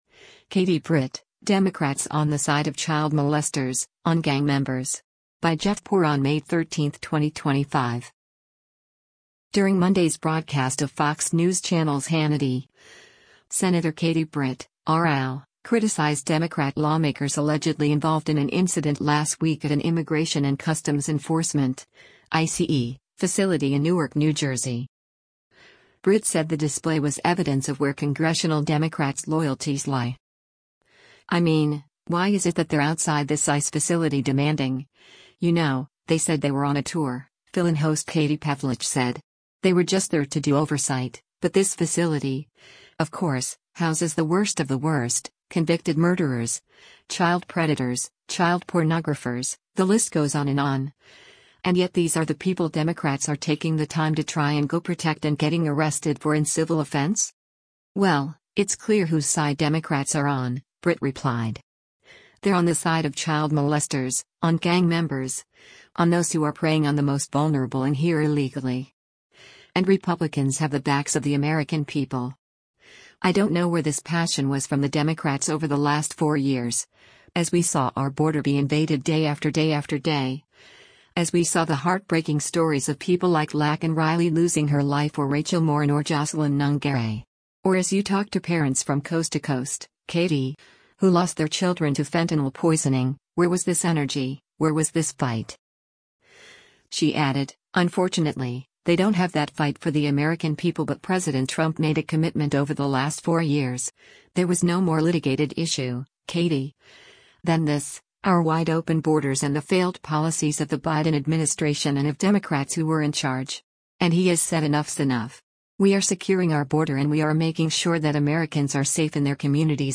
During Monday’s broadcast of Fox News Channel’s “Hannity,” Sen. Katie Britt (R-AL) criticized Democrat lawmakers allegedly involved in an incident last week at an Immigration and Customs Enforcement (ICE) facility in Newark, NJ.